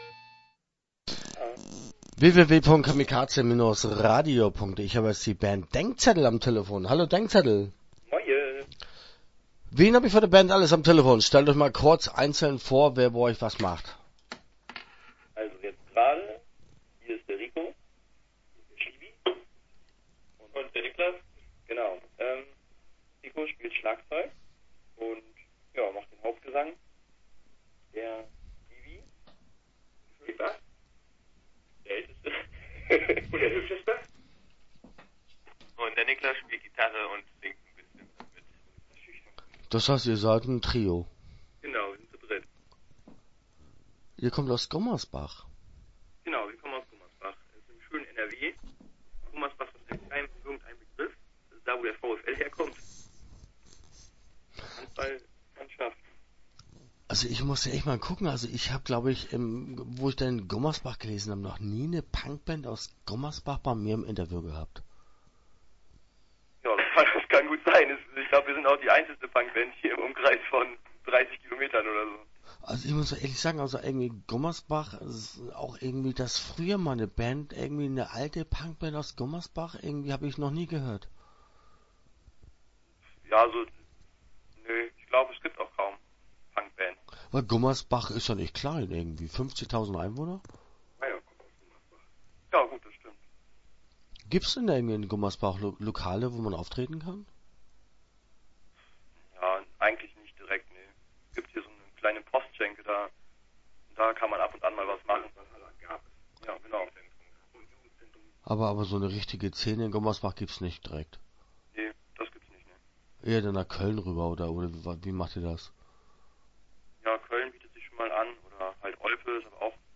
Start » Interviews » Denkzettel